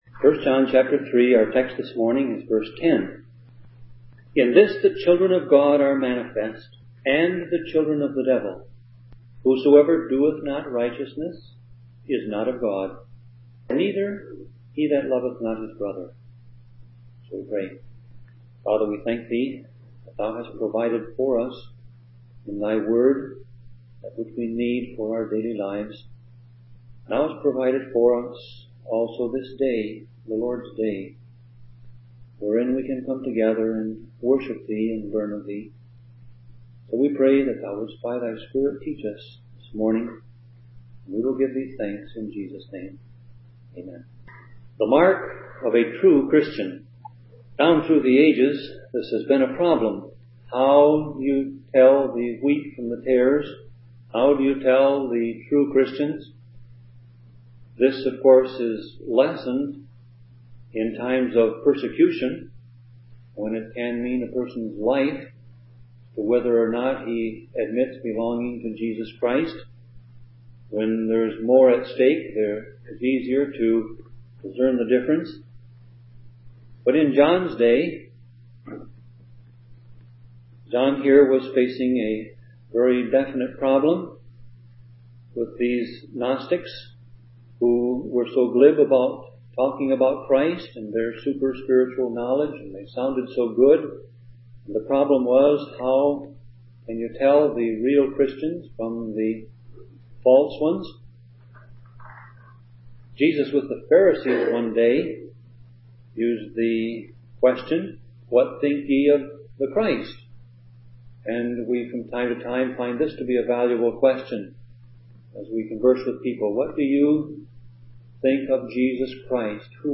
Sermon Audio Passage: 1 John 3:10 Service Type